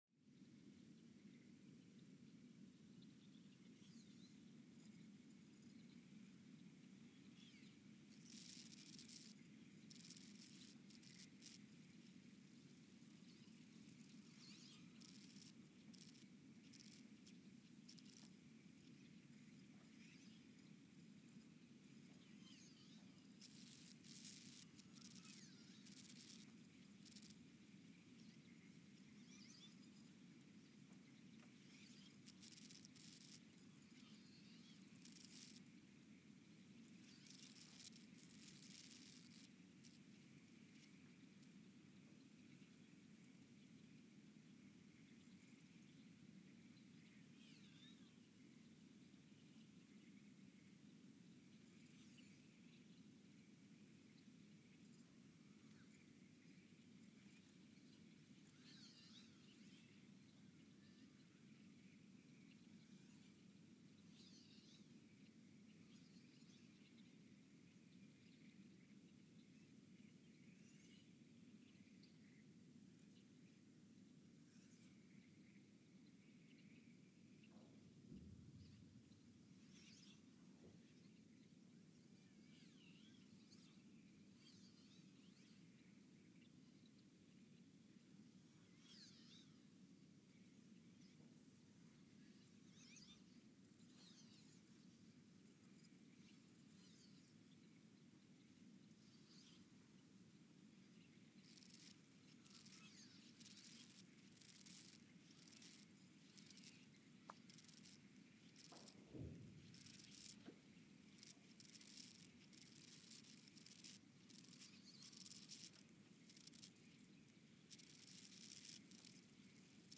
The Earthsound Project is an ongoing audio and conceptual experiment to bring the deep seismic and atmospheric sounds of the planet into conscious awareness.
Station : ULN (network: IRIS/USGS ) at Ulaanbaatar, Mongolia Sensor : STS-1V/VBB Recorder : Quanterra QX80 @ 20 Hz
Speedup : ×900 (transposed up about 10 octaves) Loop duration (real) : 168 hours Loop duration (audio) : 11:12 (stereo)